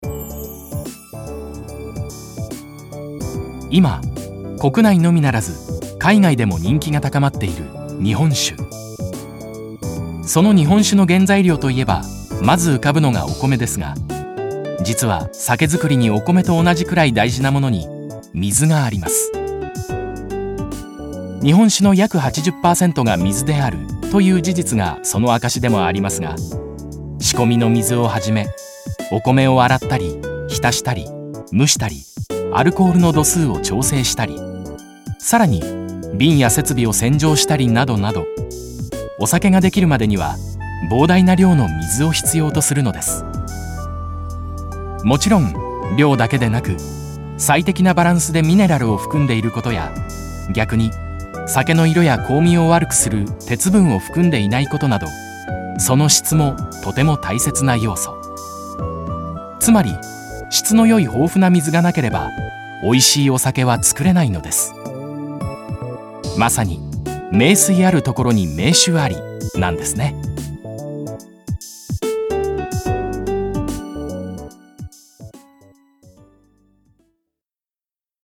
低音を活かしたナレーションでラジオCM・企業VPの他、ドラマCD・ゲームのキャラクターボイス等、幅広く活躍。 爽やかさと重厚さを使い分けるナレーションには定評がある。